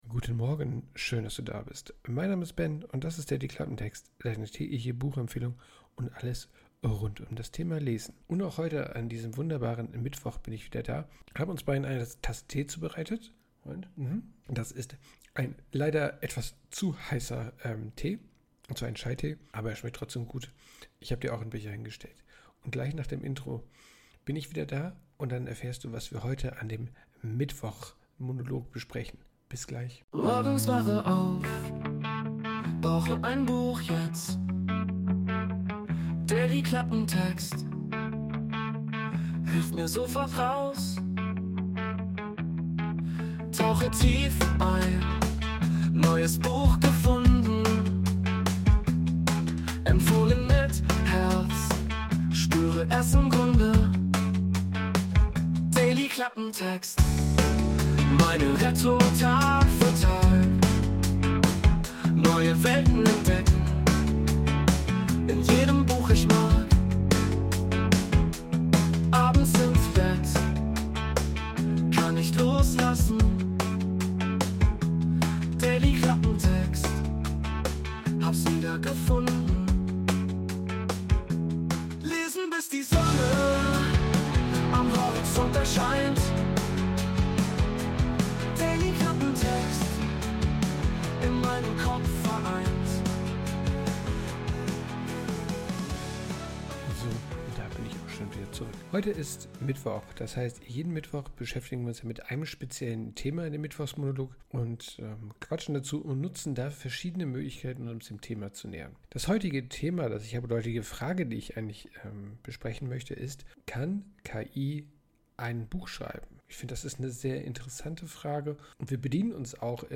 Willkommen zum Mittwochsmonolog. Heute geht es um die Frage: Kann KI Bücher schreiben?